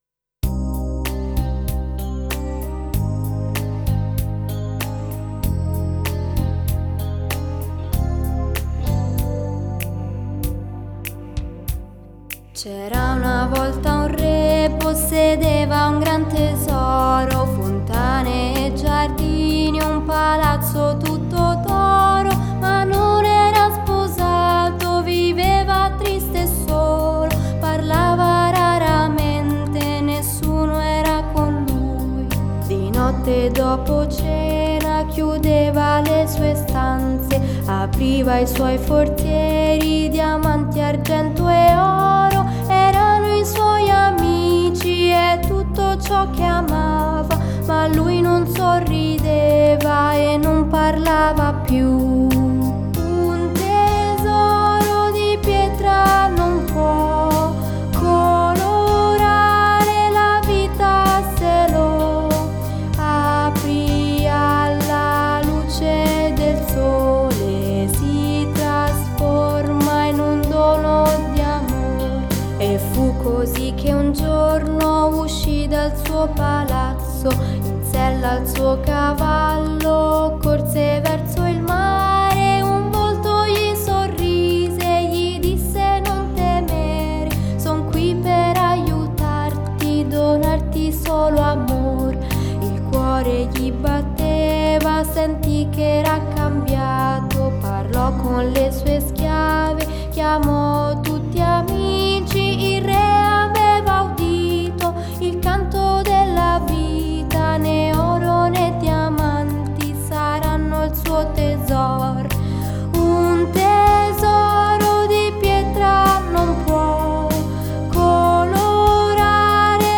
Canzoni originali